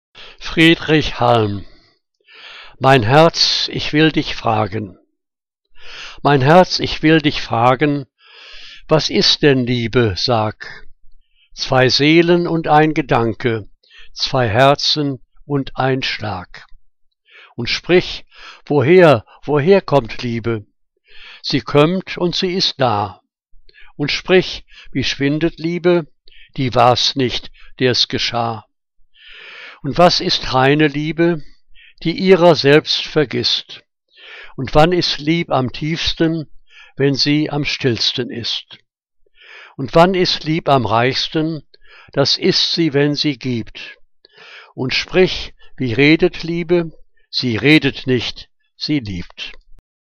Liebeslyrik deutscher Dichter und Dichterinnen - gesprochen (Friedrich Halm)